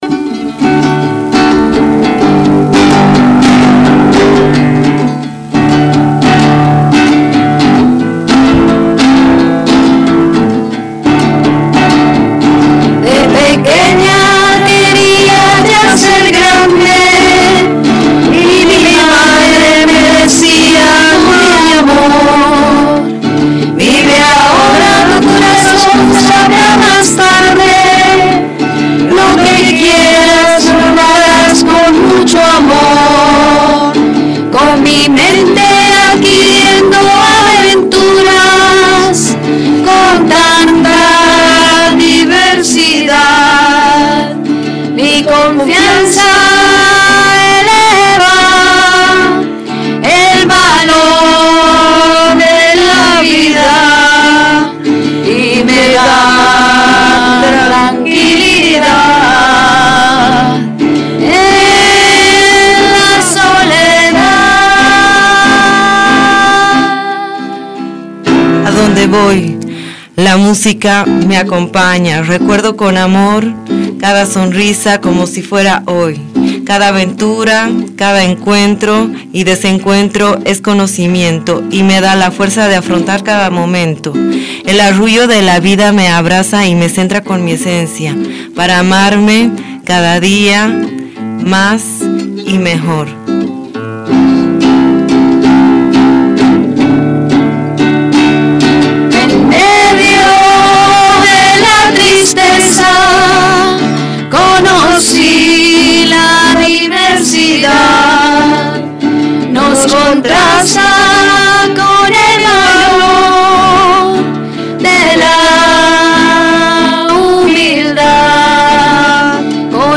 int370_poema_musical.mp3